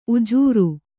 ♪ ujūru